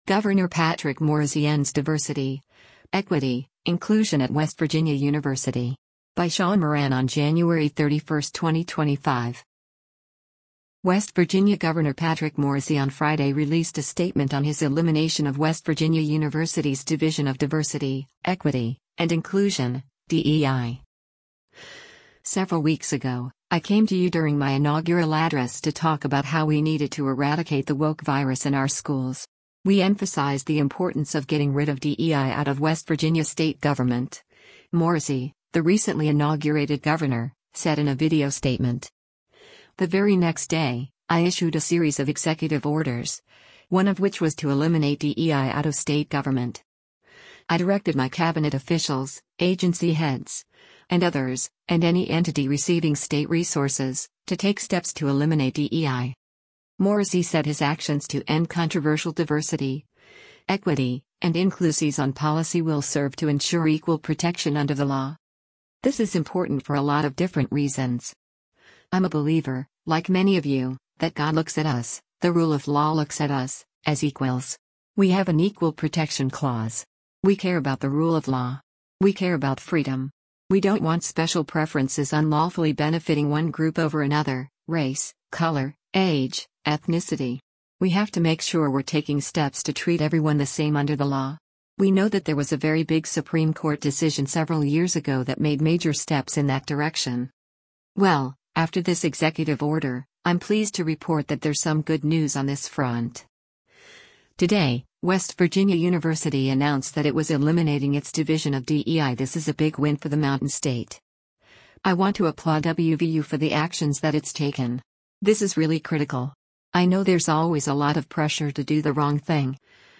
West Virginia Gov. Patrick Morrisey on Friday released a statement on his elimination of West Virginia University’s Division of Diversity, Equity, and Inclusion (DEI).